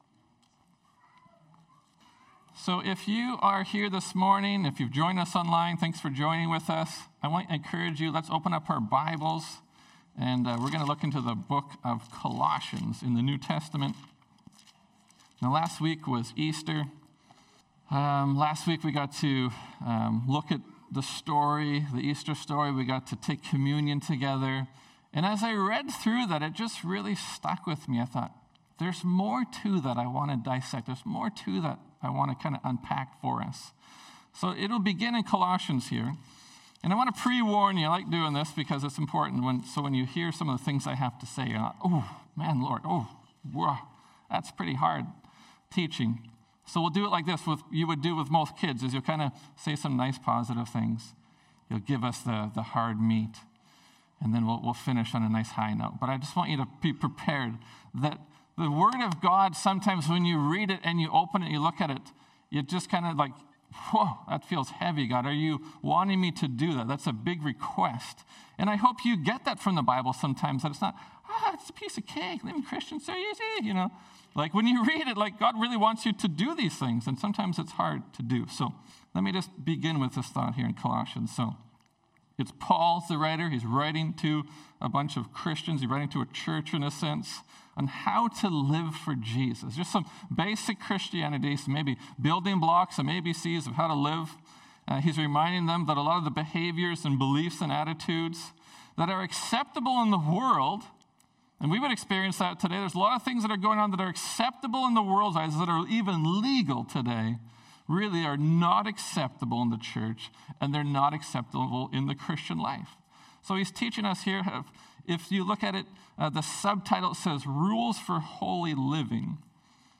Sermons | Terrace Pentecostal Assembly